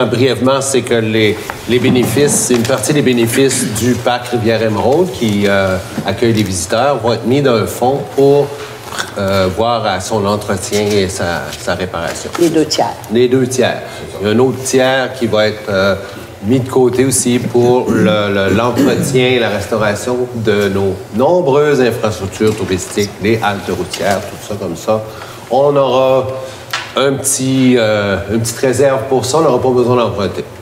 Le maire, Daniel Leboeuf, espère ainsi autofinancer les travaux nécessaires dans le parc, mais à plus large échelle, participer à l’entretien de toutes les infrastructures touristiques de Percé :